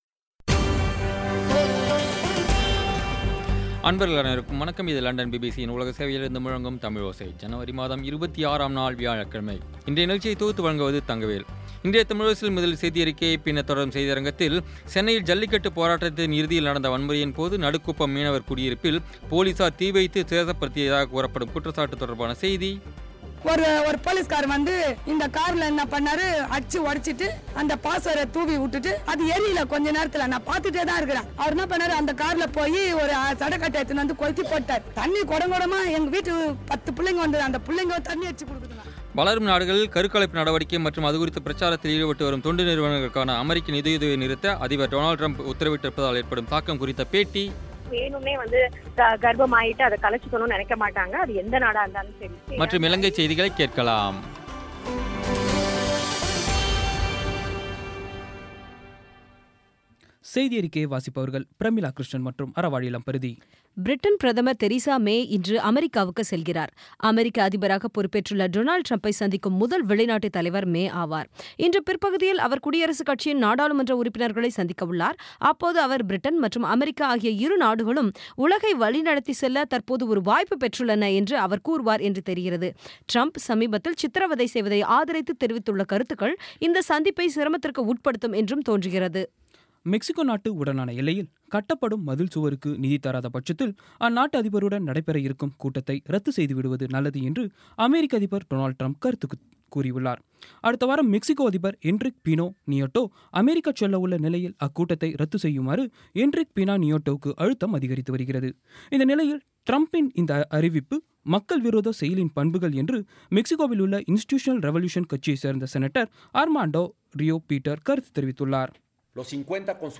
இன்றைய தமிழோசையில், சென்னையில் ஜல்லிக்கட்டுப் போராட்டத்தின் இறுதியில் நடந்த வன்முறையின்போது, நடுக்குப்பம் மீனவர் குடியிருப்பில் போலீசார் தீ வைத்து சேதப்படுத்தியதாகக் கூறப்படும் குற்றச்சாட்டு தொடர்பான செய்தி வளரும் நாடுகளில் கருக்கலைப்பு நடவடிக்கை மற்றும் அதுகுறித்த பிரசாரத்தில் ஈடுபட்டு வரும் தொண்டு நிறுவனங்களுக்கான அமெரிக்க நிதியுதவியை நிறுத்த அதிபர் டொனால்ட் டிரம்ப் உத்தரவிட்டிருப்பதால் ஏற்படும் தாக்கம் குறித்த பேட்டி மற்றும் இலங்கைச் செய்திகள் கேட்கலாம்..